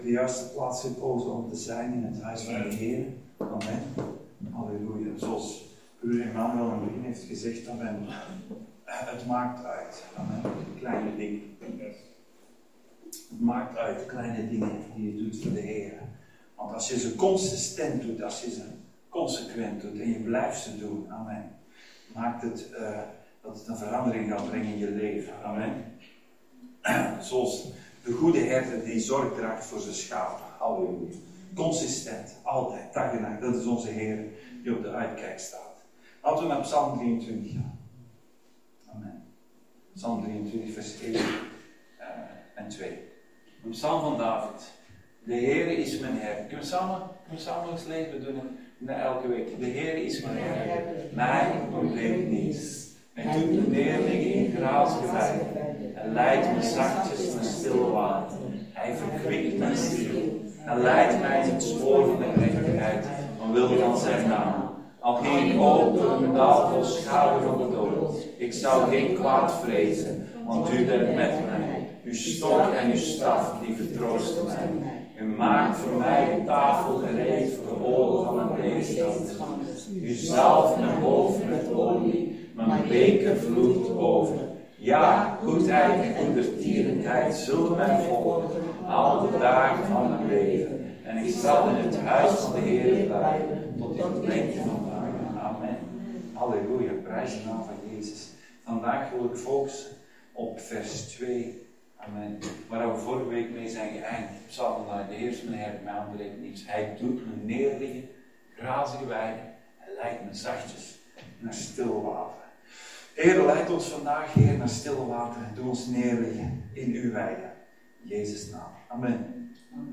Dienstsoort: Zondag Dienst